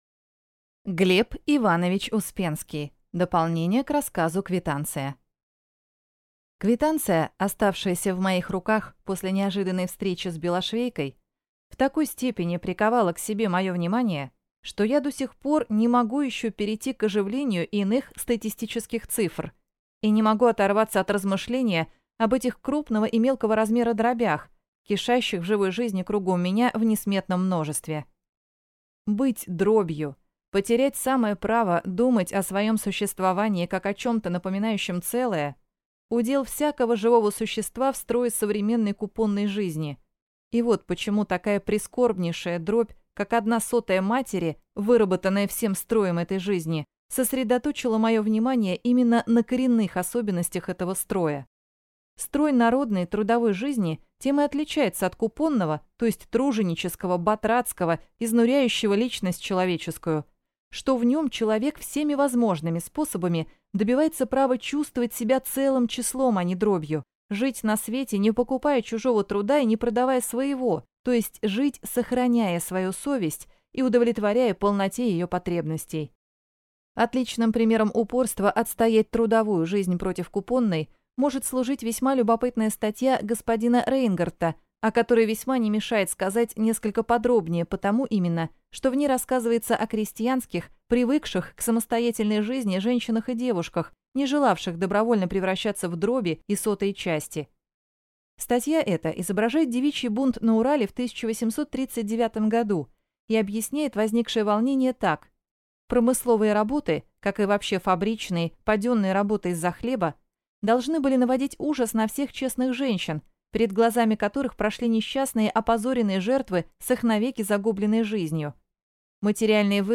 Аудиокнига Дополнение к рассказу «Квитанция» | Библиотека аудиокниг